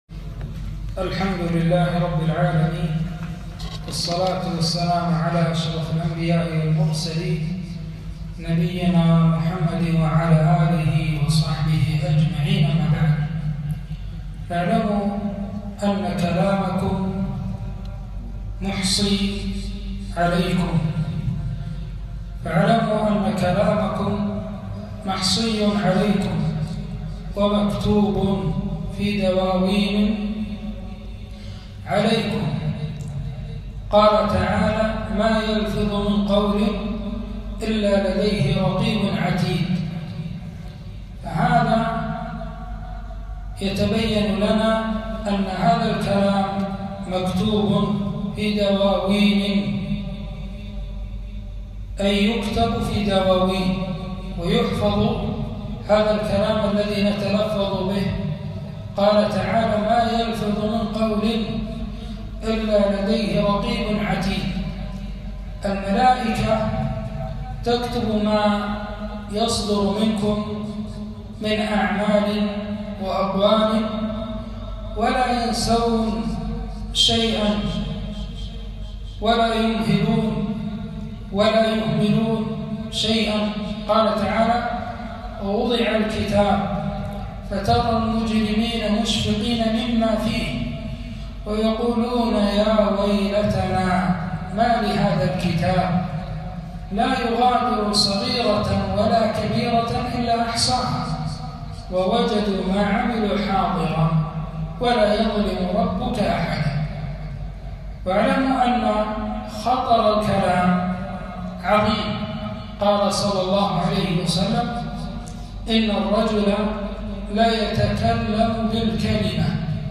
محاضرة - التحذير من آفات اللسان